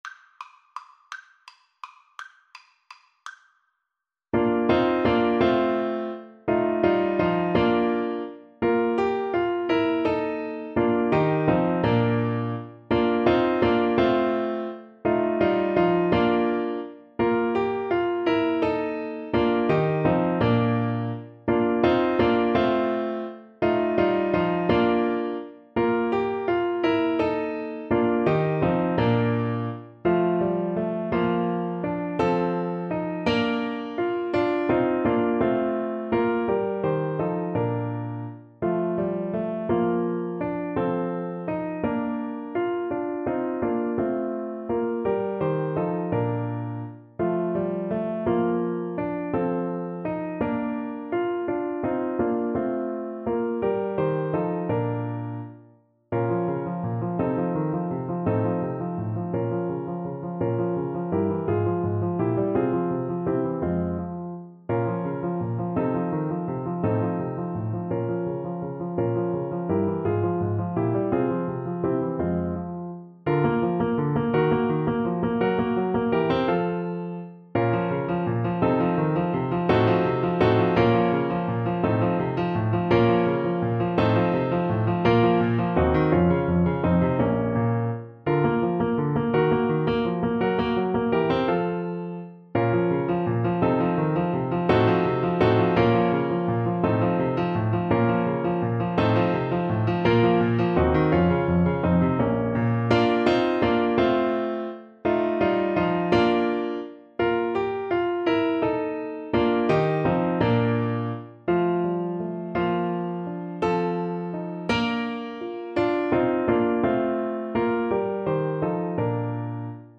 3/8 (View more 3/8 Music)
Lustig (Happy) .=56
Classical (View more Classical Saxophone Music)